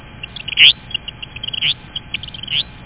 KINGBIRD.mp3